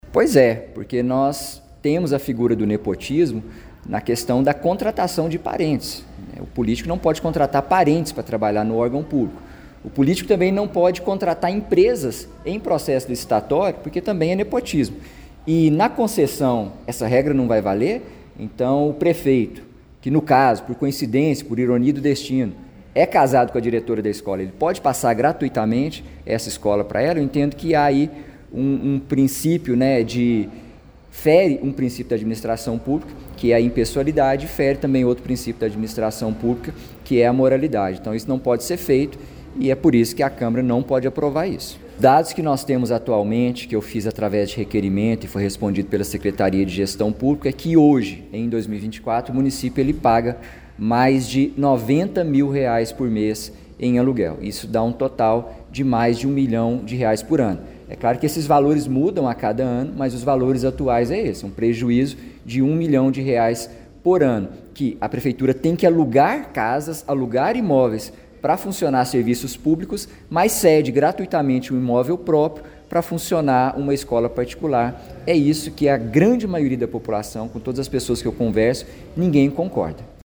O Portal GRNEWS acompanhou a reunião ordinária da Câmara Municipal de Pará de Minas nesta terça-feira, 03 de dezembro.